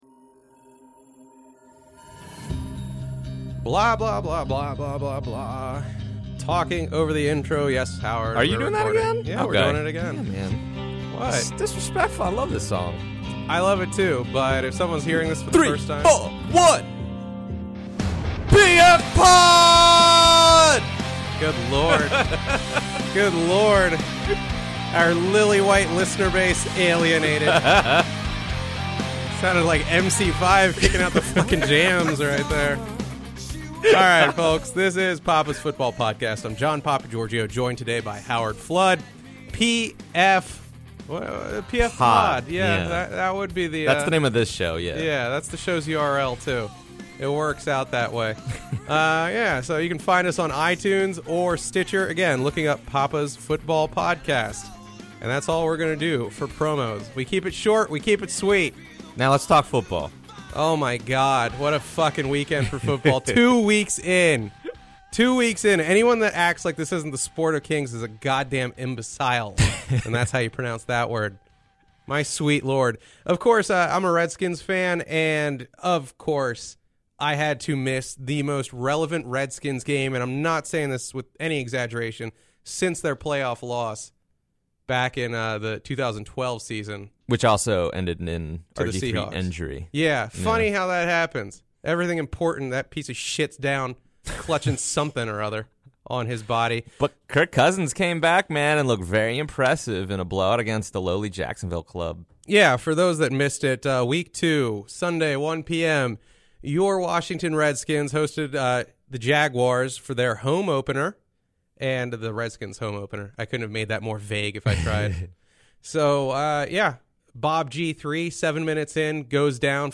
If you like your sports talk fun, fast and dirty, then like and share the episode!